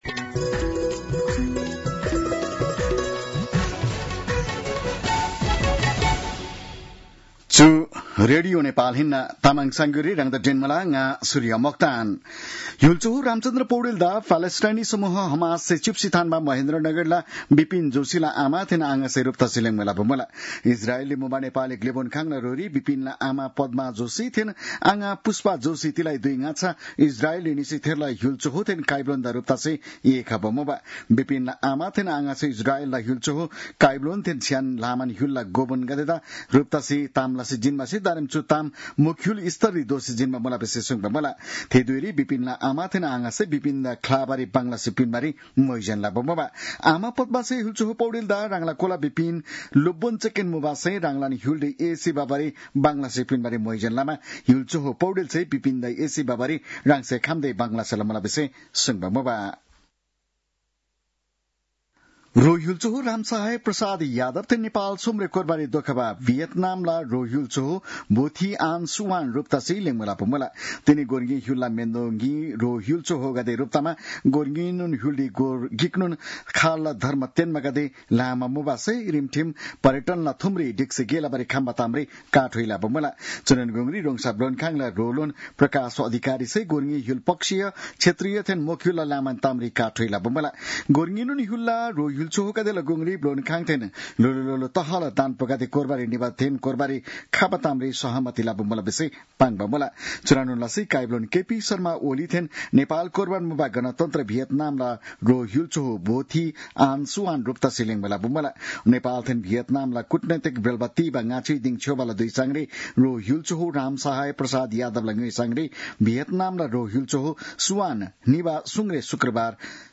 तामाङ भाषाको समाचार : ८ भदौ , २०८२